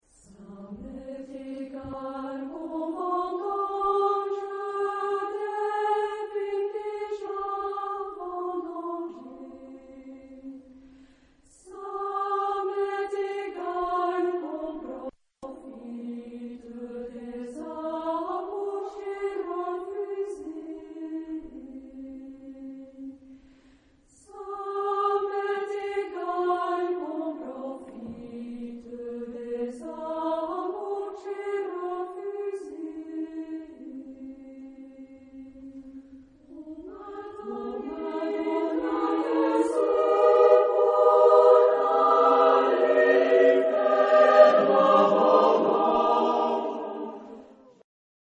Genre-Style-Forme : Chanson ; Folklore ; Profane
Caractère de la pièce : énergique ; affectueux ; langoureux ; andante
Type de choeur : SATB  (4 voix mixtes )
Tonalité : sol mineur